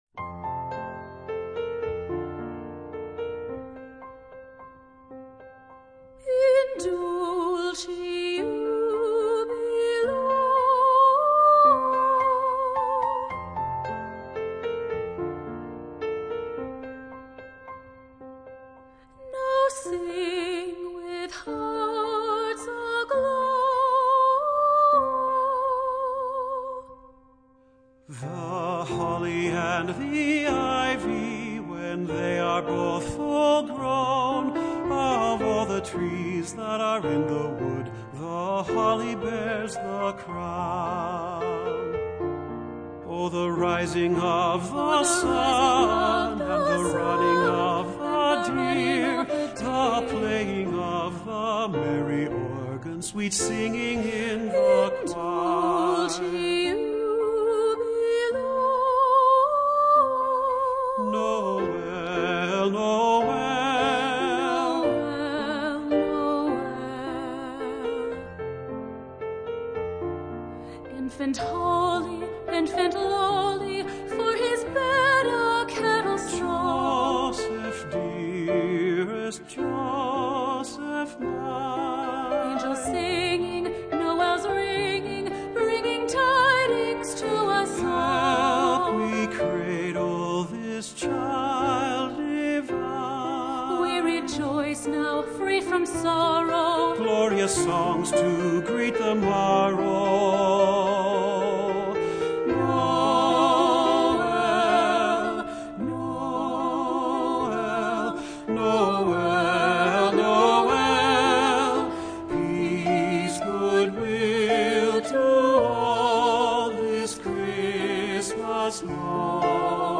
Voicing: Vocal Duet